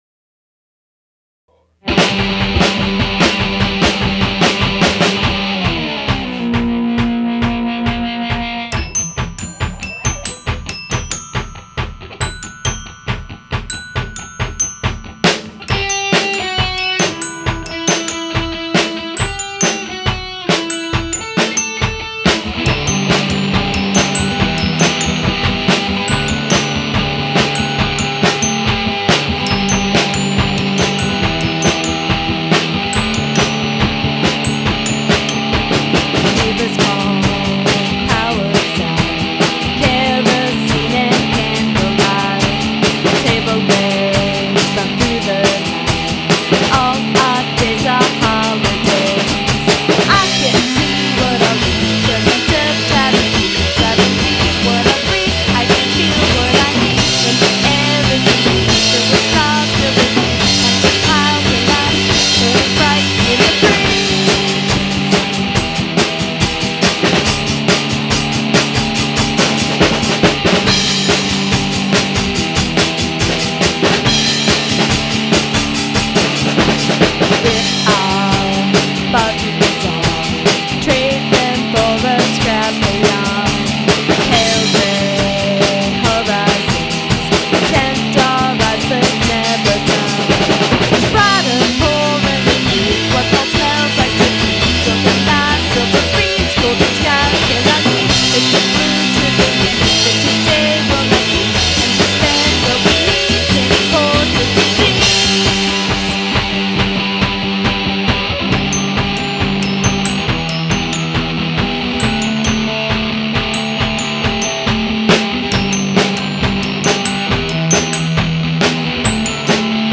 3-2-2010 Recording Session